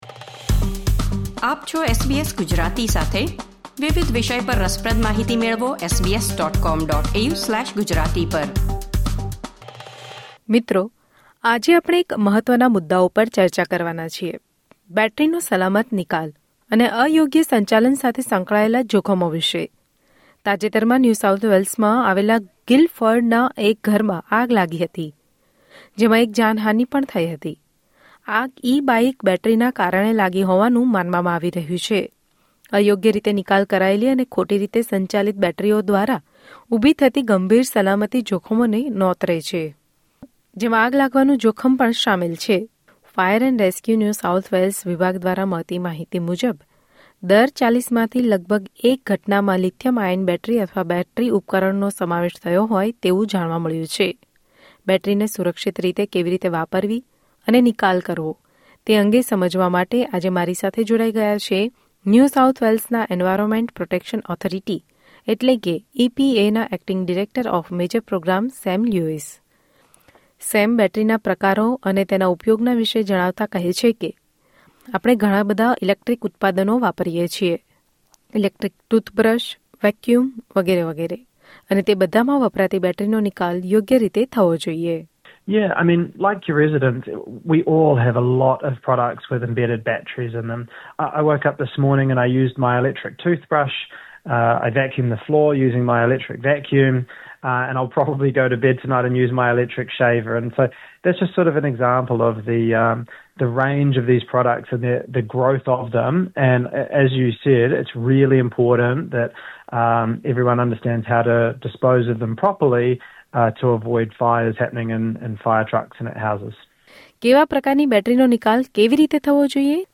ઘરમાં વપરાતા વિવિધ ઉપકરણો જેવાં કે, ઇલેક્ટ્રીક બ્રશ, વેક્યુમક્લિનર, મોબાઇલ ફોન વગેરેમાં વપરાતી લિથિયમ-આયન બેટરીનો જો યોગ્ય નિકાલ કરવામાં ન આવે તો આગ બનવાનું કારણ બની શકે છે. બેટરીનો ઉપયોગ અને તેના નિકાલ વિશે વધુ માહિતી મેળવો અહેવાલમાં.